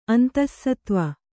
♪ antassatva